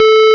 But some sounds are played incorrectly, example a beep sound with 11.025 Hz:
You know that your beeb.wav is a square wave :?:
Square waves sounds always 'distorted'.
beep.wav